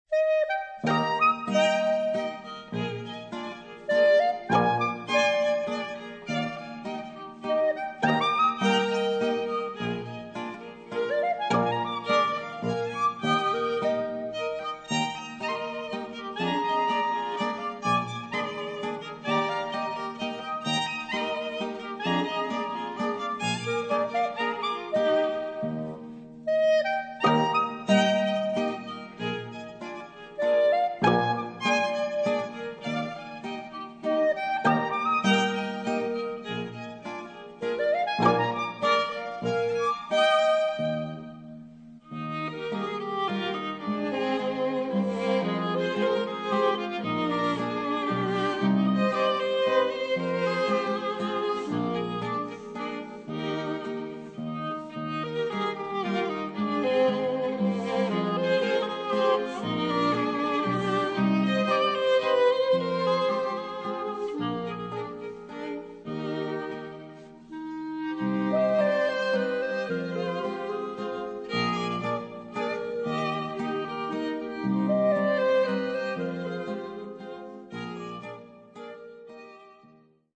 ** Quartett mit G-Klarinette